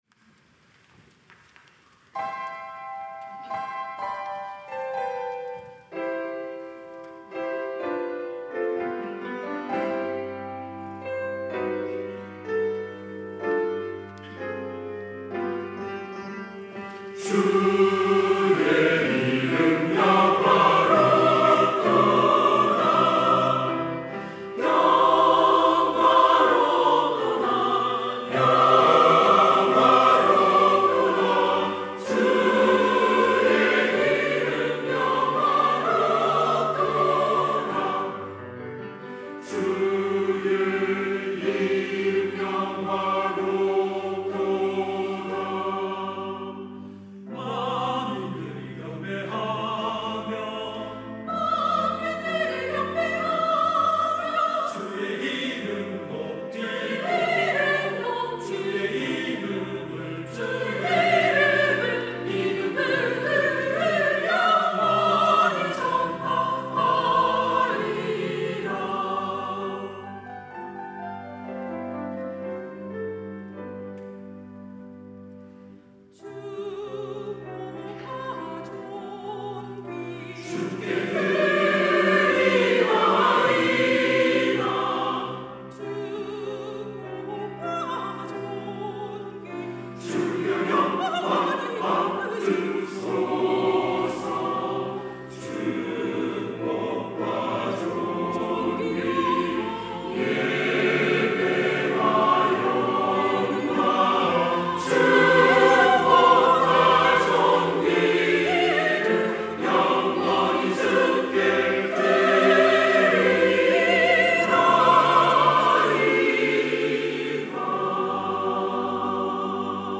시온(주일1부) - 주의 이름 영화롭도다
찬양대